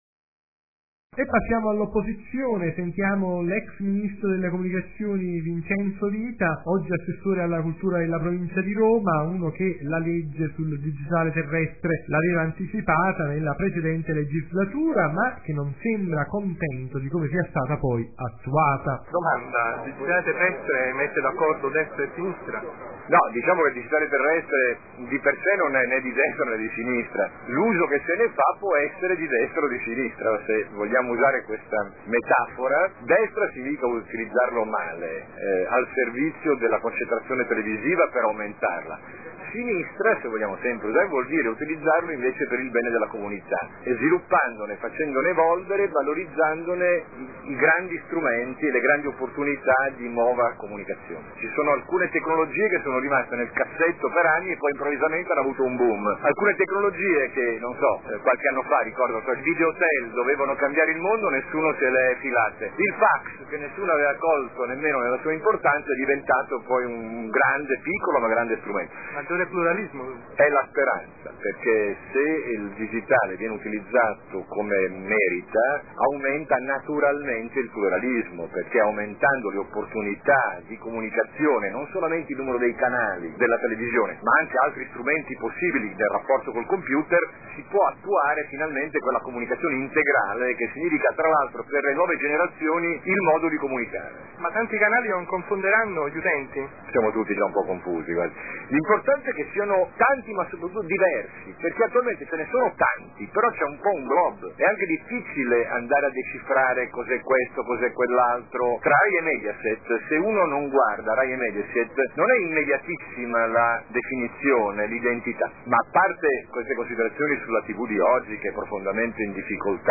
Oggi ci stiamo occupando del passaggio della televisione al digitale terrestre, con una serie di interviste raccolte nei giorni  scorsi ad u convegno itinerante promosso  dell’’Istituto per lo studio dell’Innovazione nei media e per la Multimedialità ISIMM, la Fondazione Bordoni e i Corecom di Lazio, Campania e Sicilia.